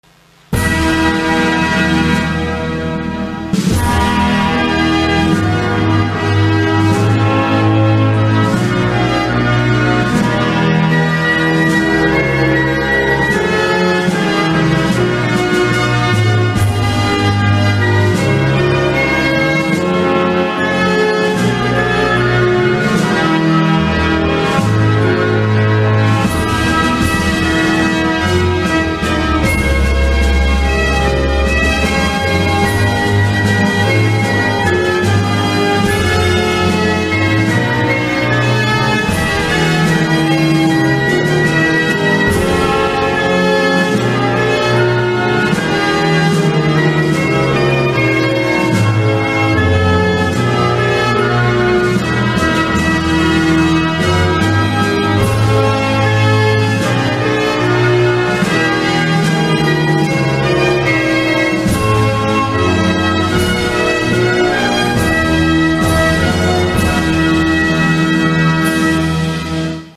Короткая инструментальная композиция